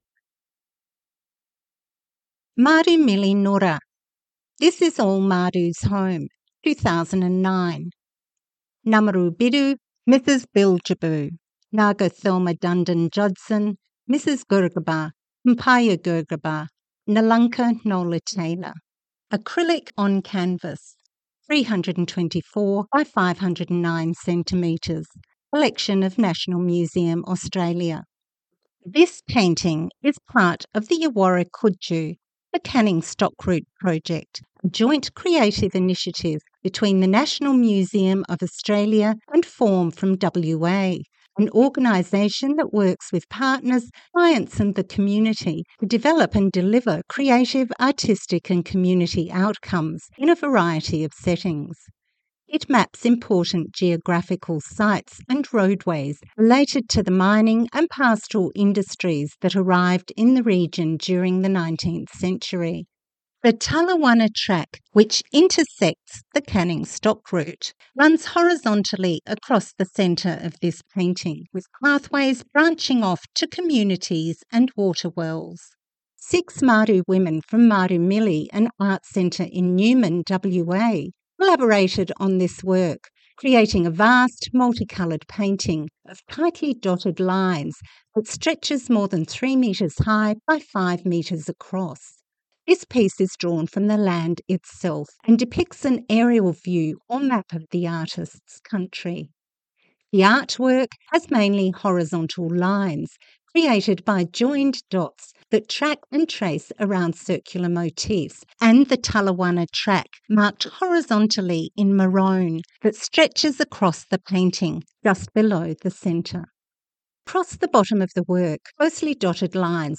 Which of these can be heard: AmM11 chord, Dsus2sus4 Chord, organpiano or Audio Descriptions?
Audio Descriptions